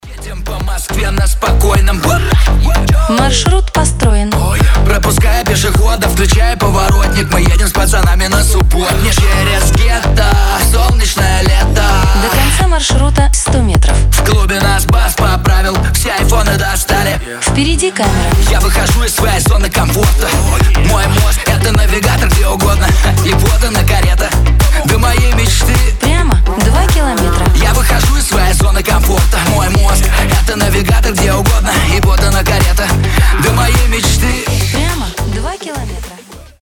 • Качество: 320, Stereo
Club House
G-House